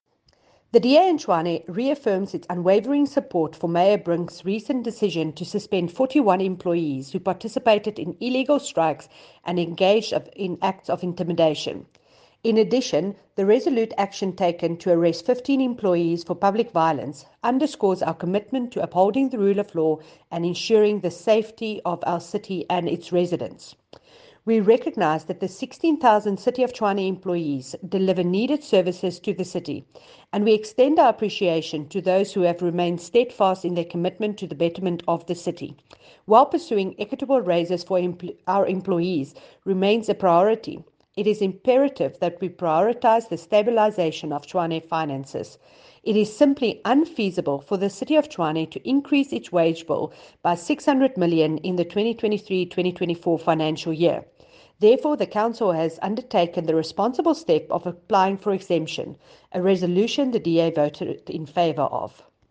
Please find soundbites by Cllr Jacquie Uys, Tshwane Caucus Chairperson in English